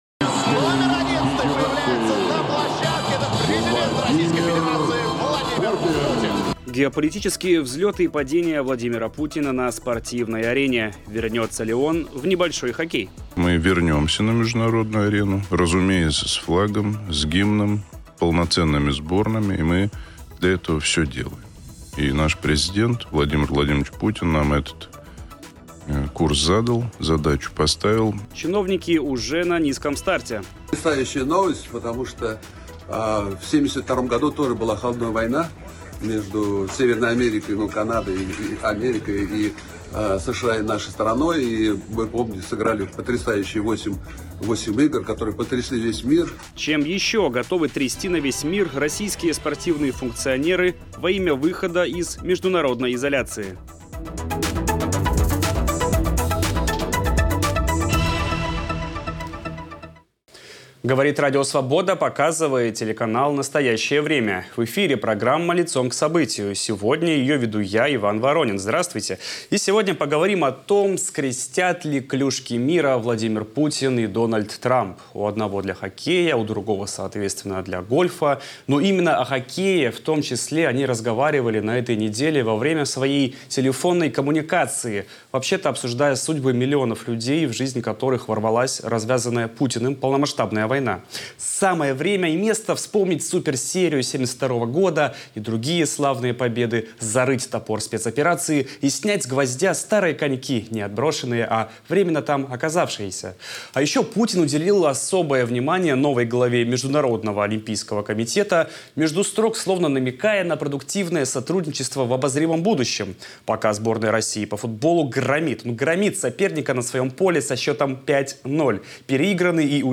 Как Кремль продвигает свои нарративы через спорт? Об этом в эфире "Лицом к событию" говорим